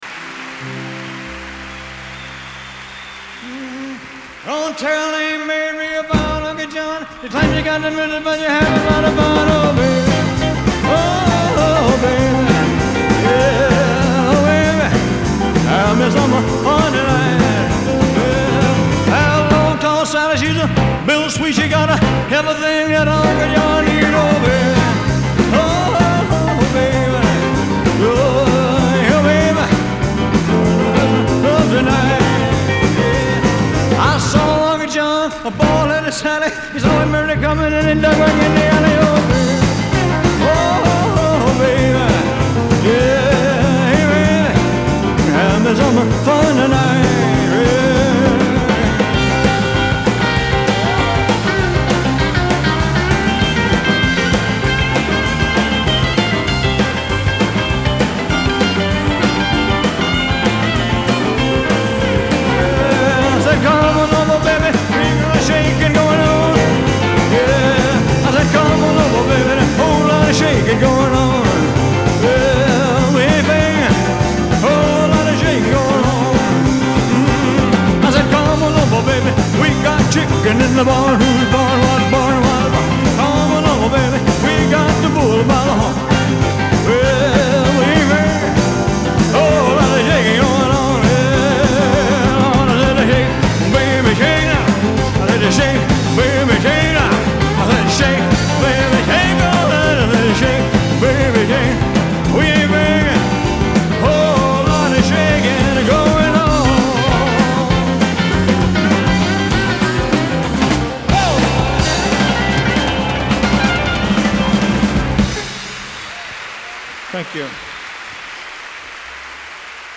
Genre : Rock